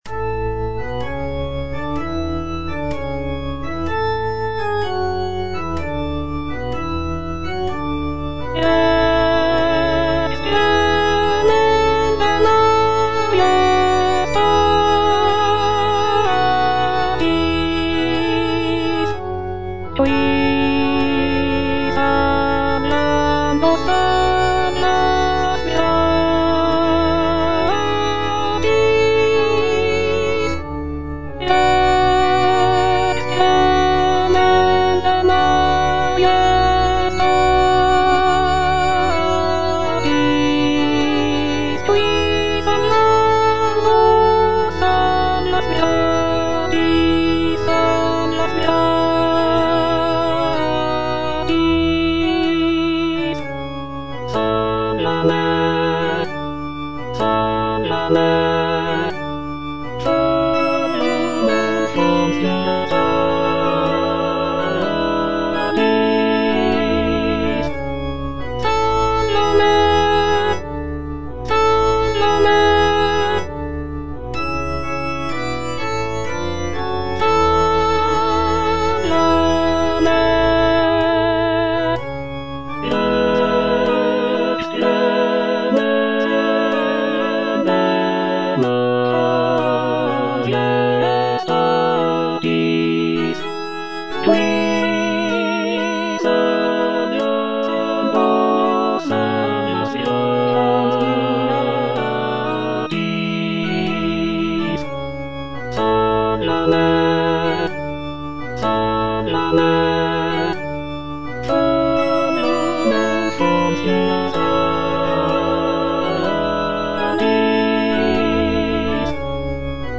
The piece features lush harmonies, soaring melodies, and powerful choral sections that evoke a sense of mourning and reverence.
F. VON SUPPÈ - MISSA PRO DEFUNCTIS/REQUIEM Rex tremendae - Alto (Voice with metronome) Ads stop: auto-stop